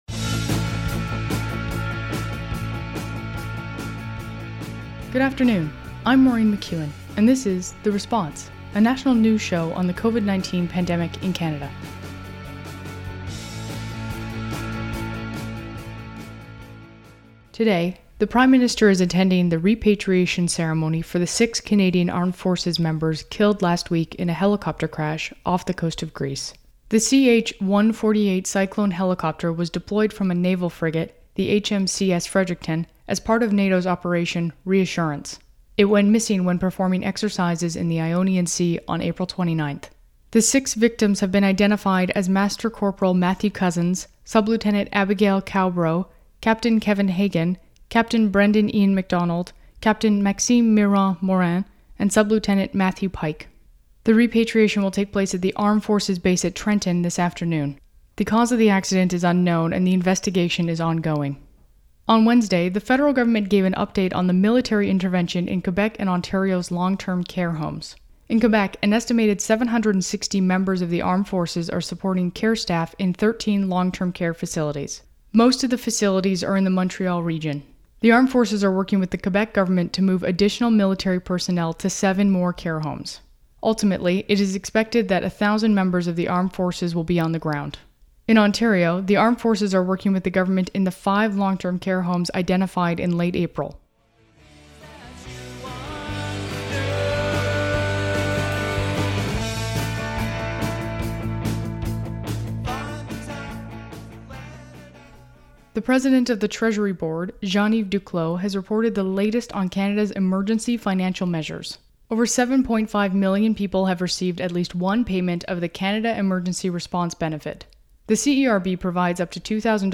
National News Show on COVID-19
Credits: Audio clips: Canadian Public Affairs Channel.
Theme: "Headed South" by The Hours.
Type: News Reports
192kbps Stereo